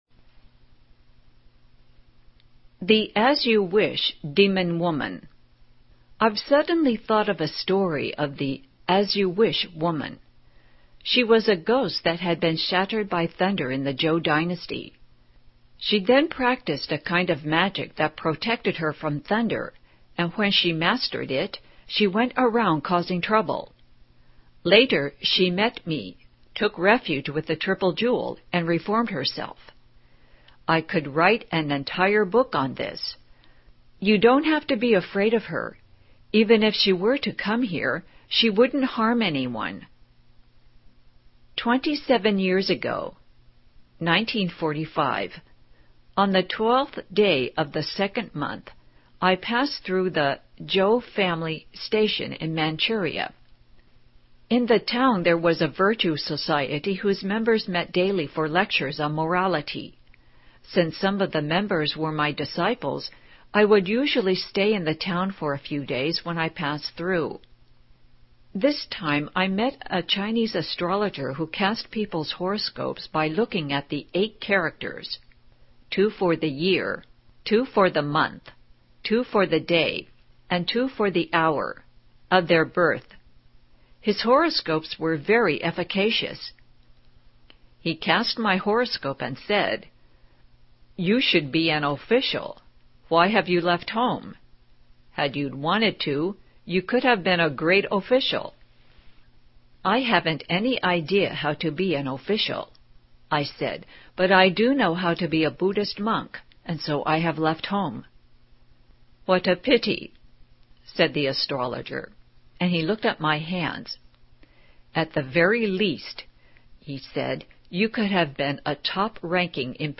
십법계불리일념심 오디오북입니다.